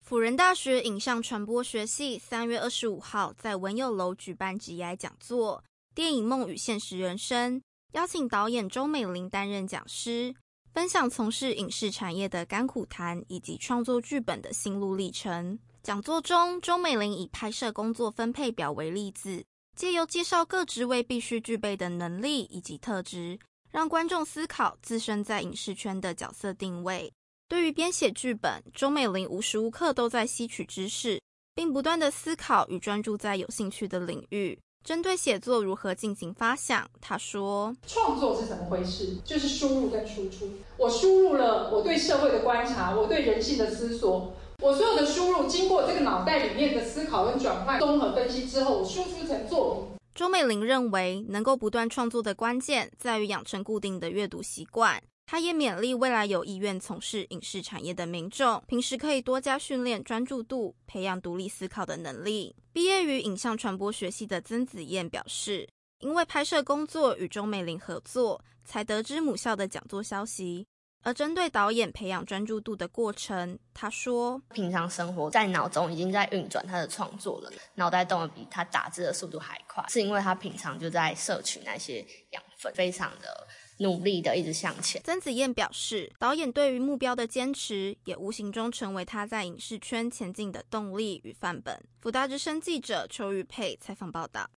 採訪報導